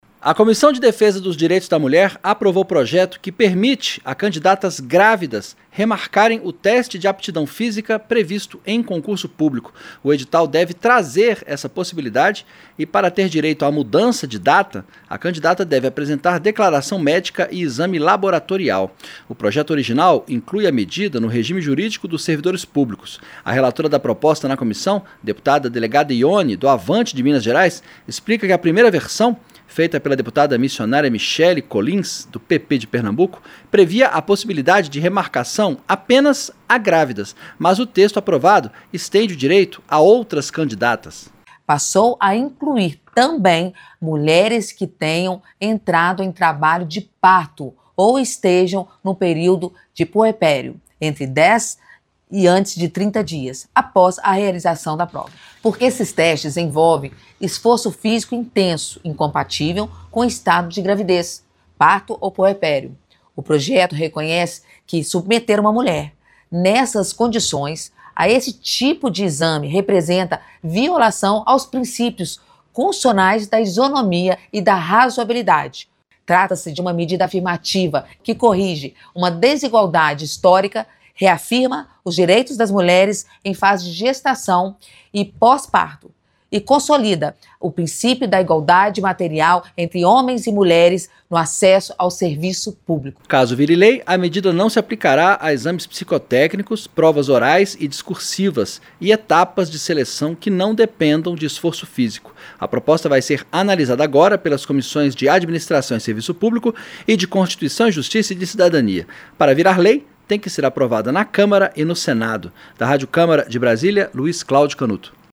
COMISSÃO DA CÂMARA APROVA PROPOSTA QUE AMPLIA DIREITOS ÀS MULHERES QUE PRESTAREM CONCURSO PÚBLICO. SAIBA OS DETALHES COM O REPÓRTER